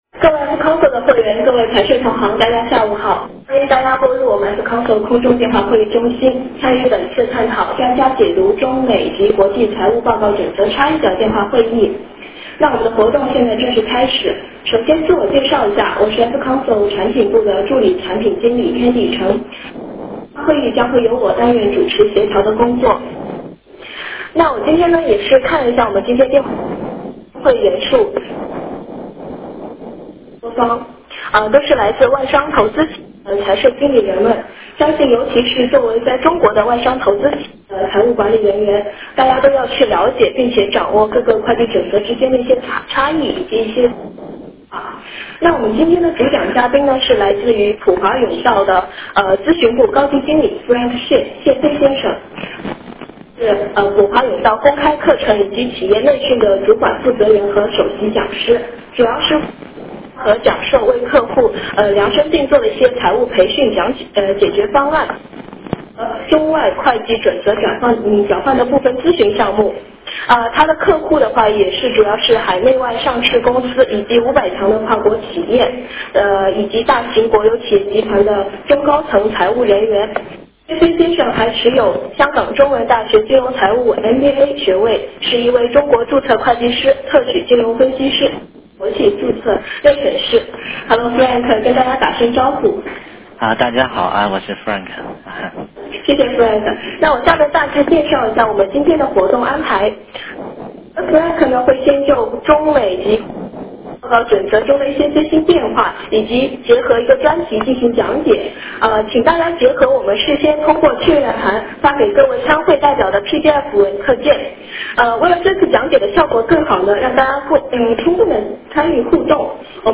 电话会议